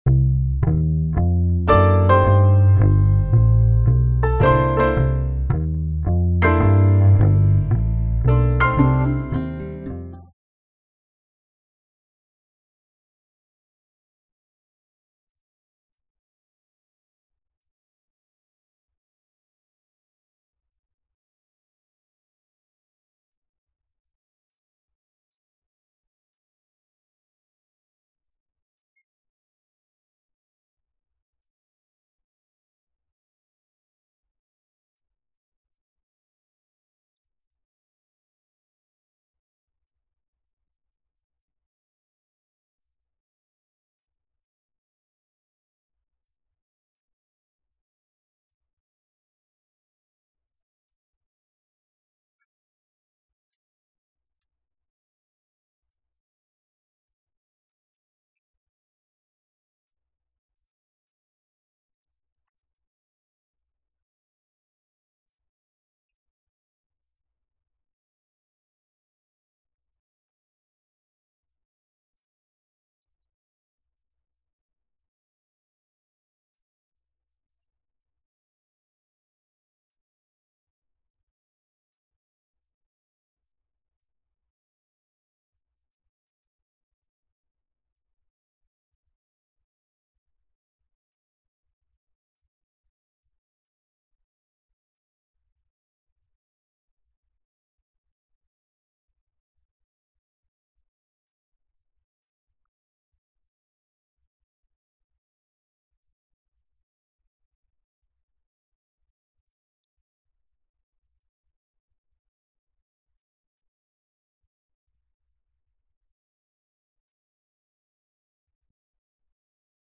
Sermon
Service Type: Sunday Worship